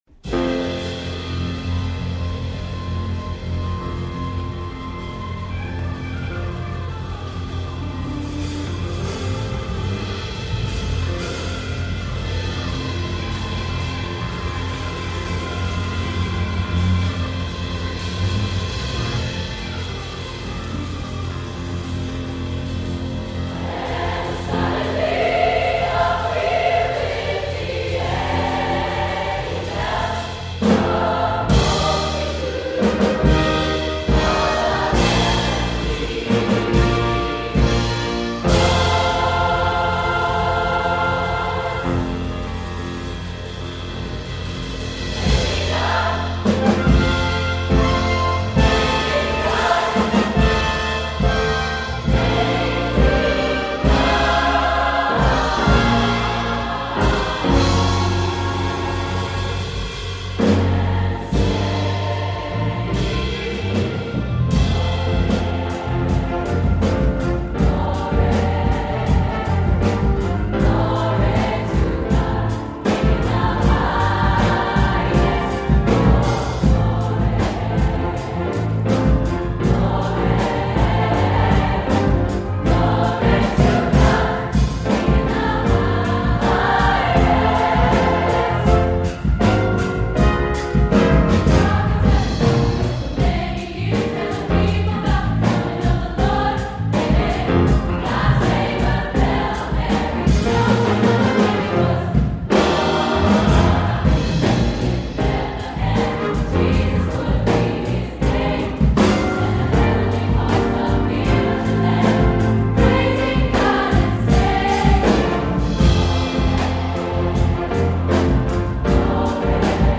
Voicing: SATB with Piano and Optional Instruments